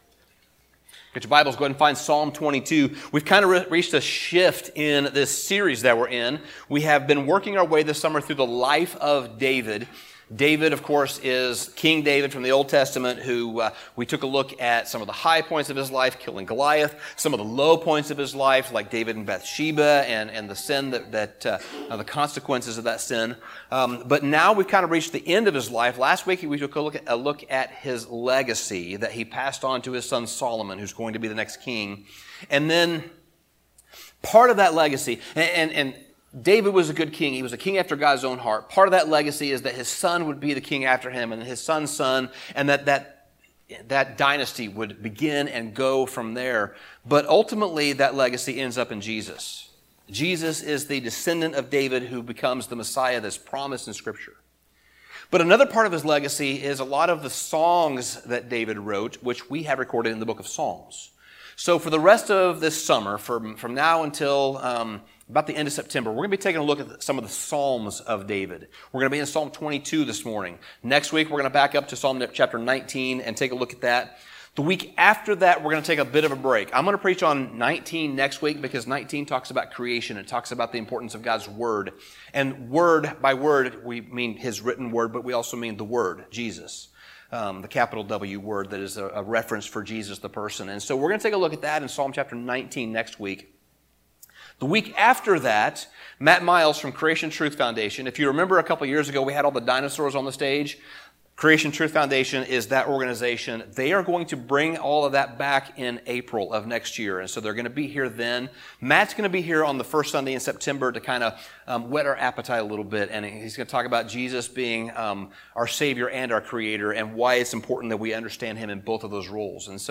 Sermon Summary We've reached a turning point in our series.